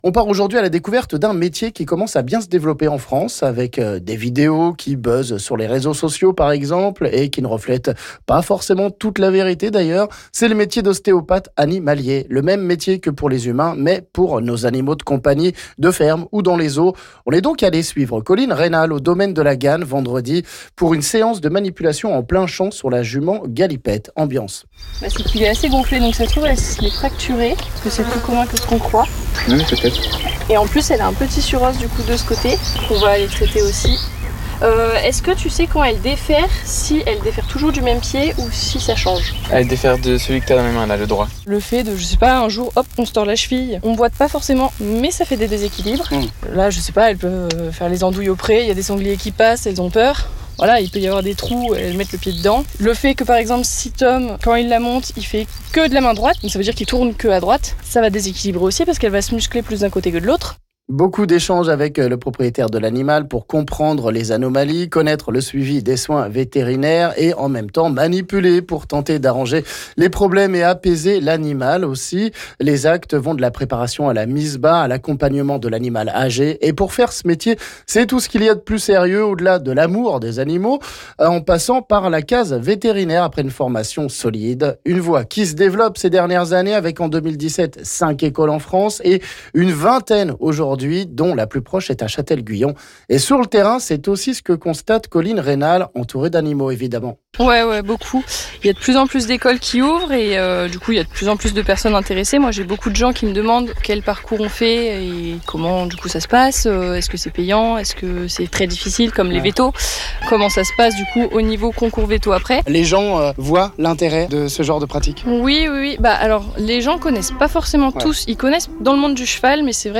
lors d’une séance à la Ganne sur une jument